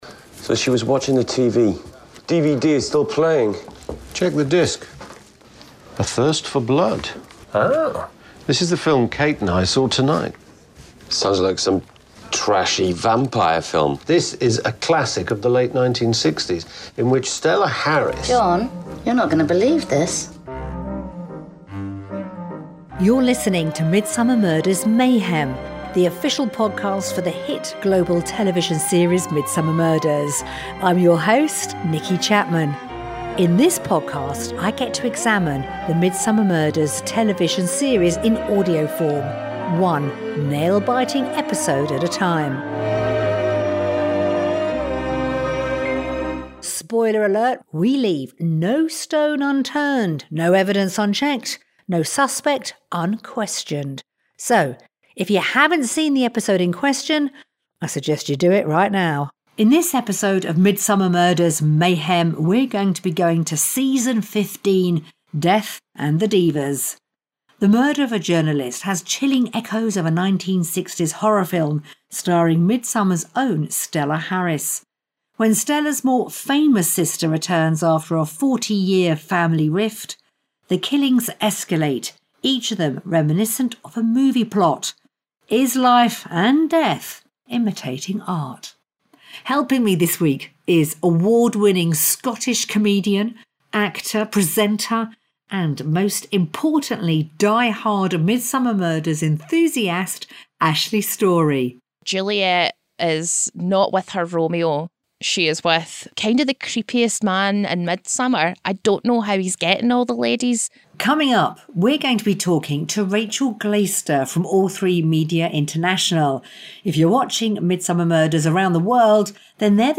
Host Nicki Chapman is joined by Ashley Storrie.